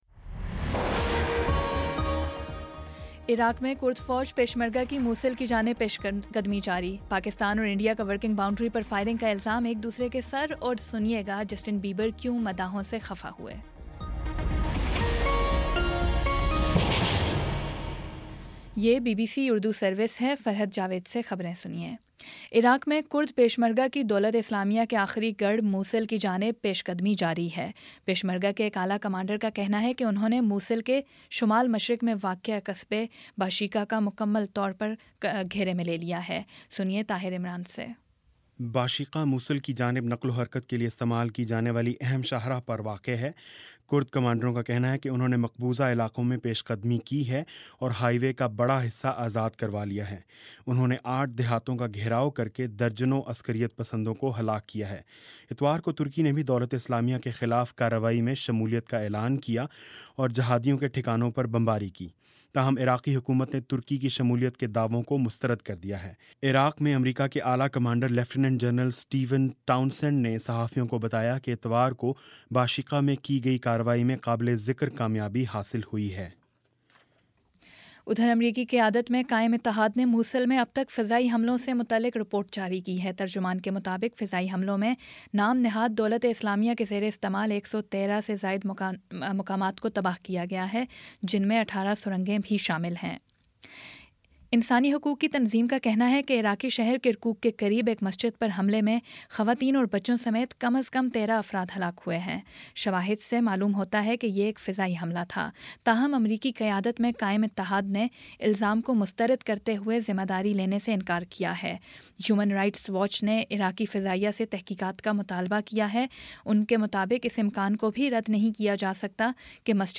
اکتوبر24 : شام سات بجے کا نیوز بُلیٹن